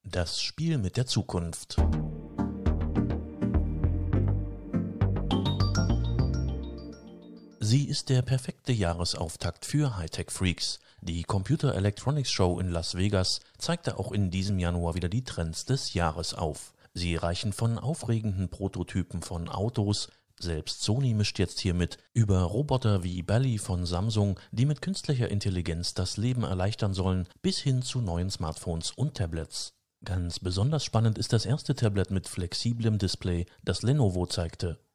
Das sind Werbespots, Hörbücher, Vertonungen und Telefonansagen, die ich im eigenen Studio produziere.
Voiceover / News: